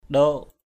/ɗo:ʔ/